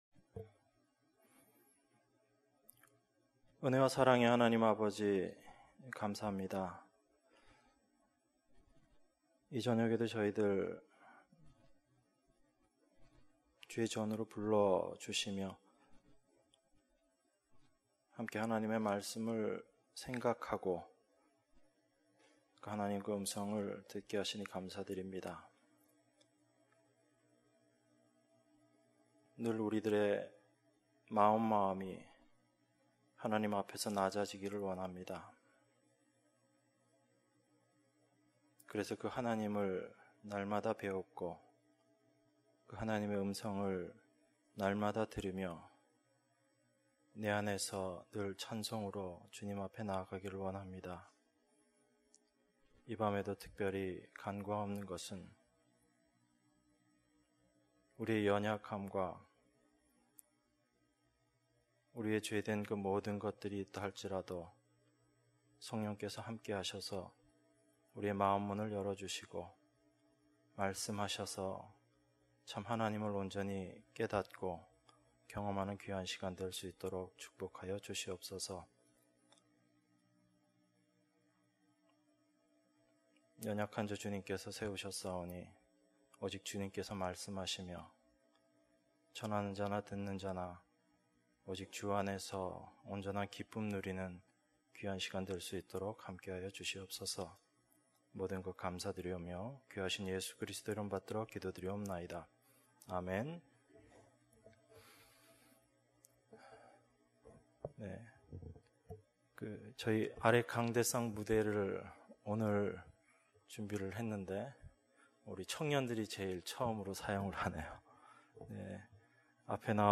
수요예배 - 요한복음 11장 1절~16절